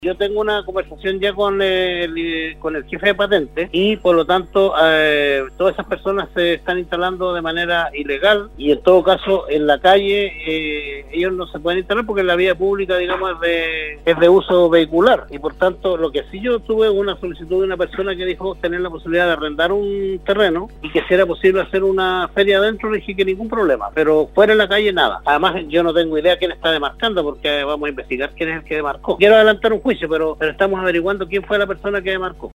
Ante las distintas llamadas y mensajes sobre la instalación de feriantes en la avenida Circunvalación entre Van Buren y Luis Flores para la conocida Feria de la Candelaria, que en días pasados había sido suspendida por la Municipalidad de Copiapó, el alcalde Marcos López, sostuvo un contacto telefónico con Nostálgica donde explicó la situación que se está presentando en la comuna.